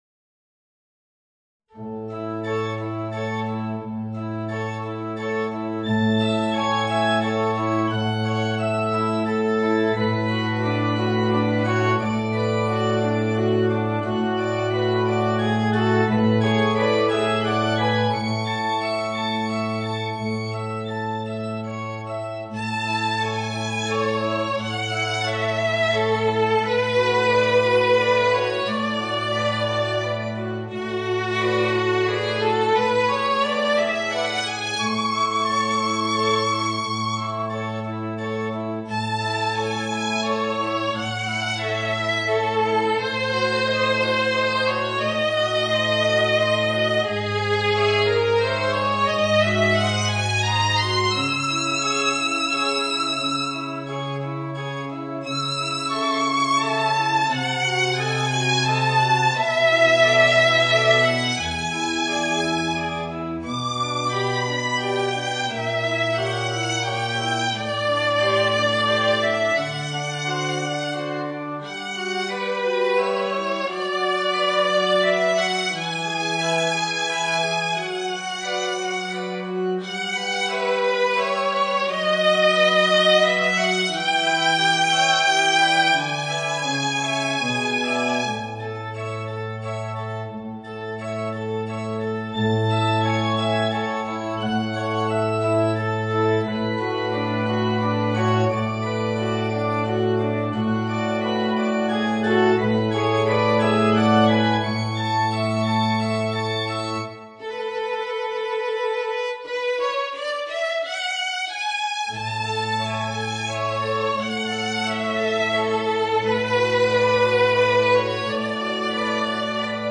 Voicing: Violin and Organ